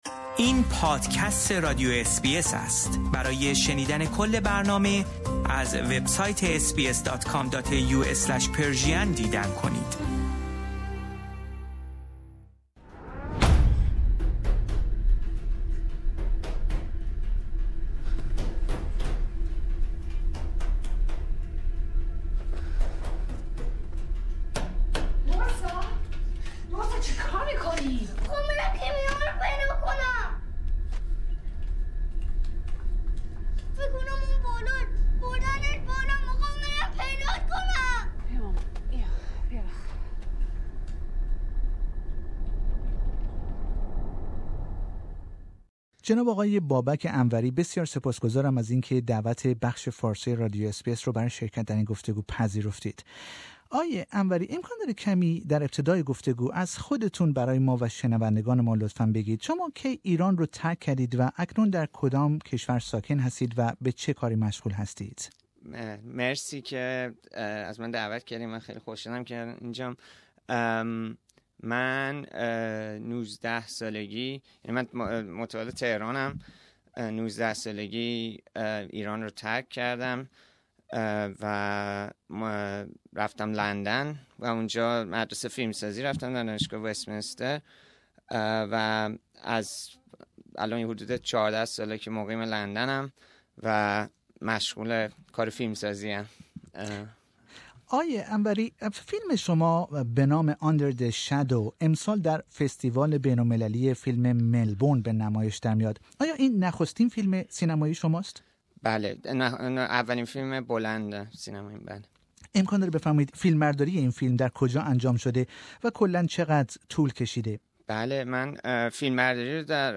In an interview with SBS Persian, Babak speaks about his first feature film that is screening at Melbourne International